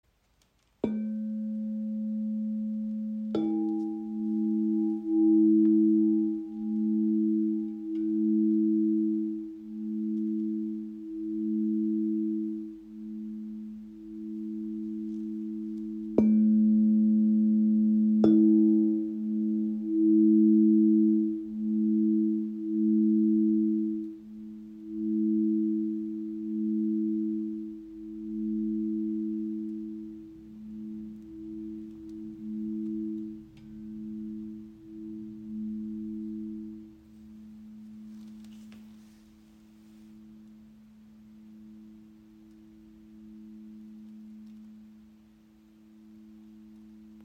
Die Wave C/G in 432 Hz ist ein handgefertigtes Klanginstrument in der heiligen Quinte das Harmonie, Ruhe und Energiefluss schenkt.
Der Klang breitet sich weich und klar im Raum aus, ohne zu dominieren, und lädt Dich ein, langsamer zu werden, tiefer zu atmen und wieder bei Dir selbst anzukommen.